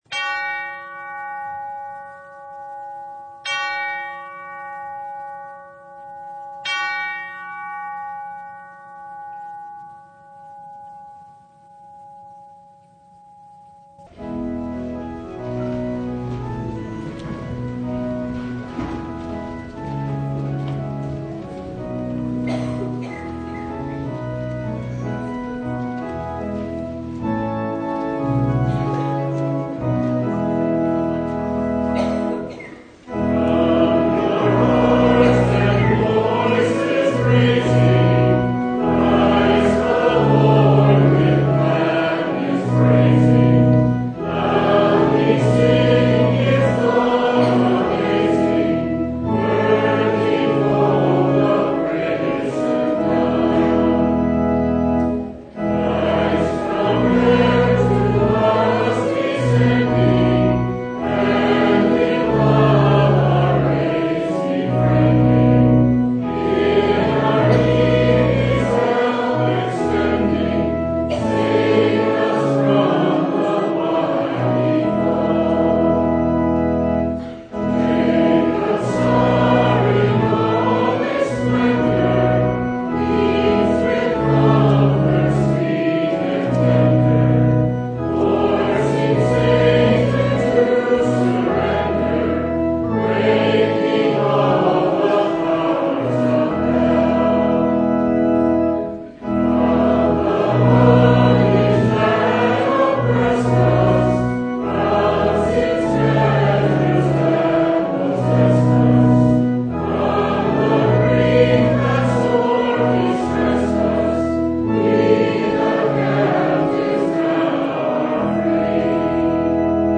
Christmas Eve Vespers (2023)
Full Service